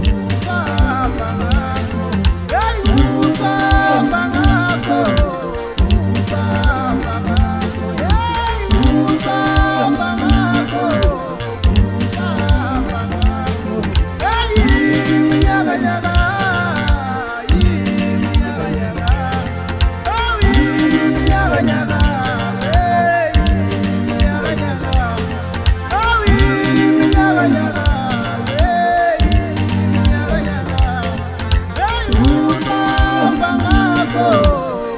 Afro-Beat African